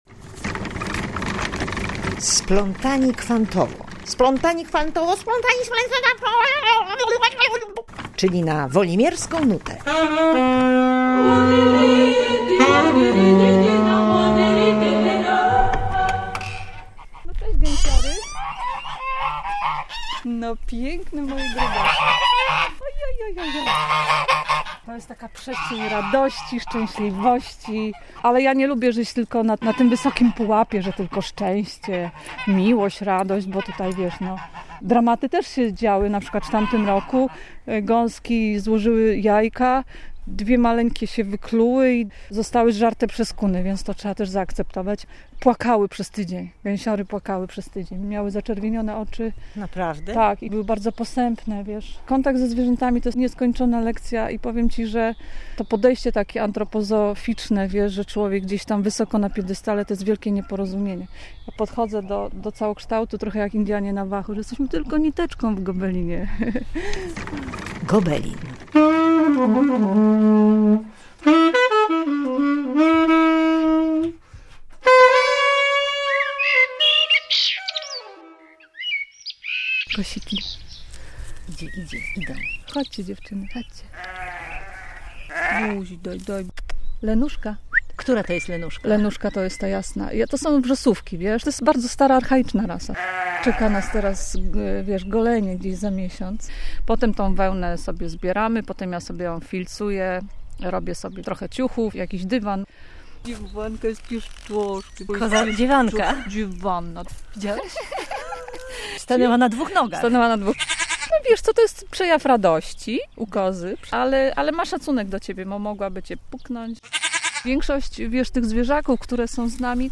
x1r550f6gjostnu_reportaz_splatani_kwantowo-_czyli_n.mp3